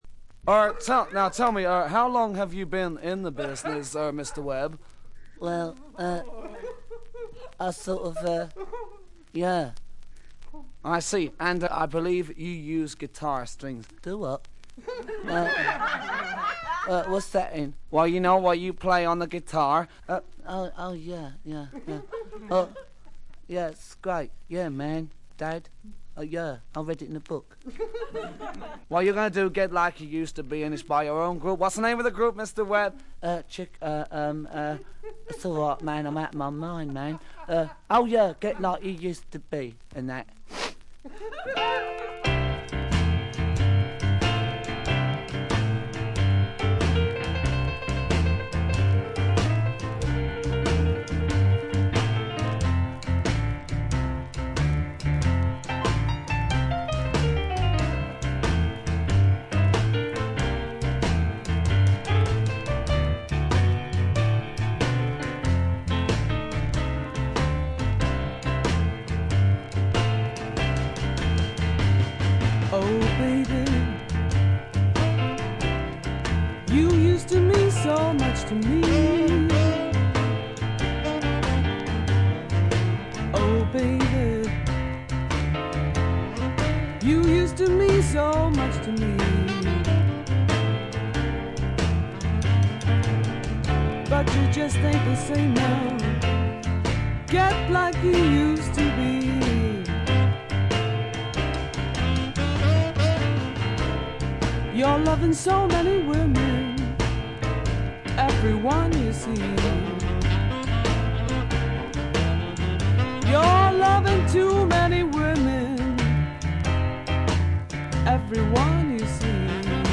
チリプチや散発的なプツ音はそこそこ出ますが鑑賞を妨げるようなものではありません。
英国ブルースロック名作中の名作。
初期モノラル・プレス。
試聴曲は現品からの取り込み音源です。（ステレオ針での録音です）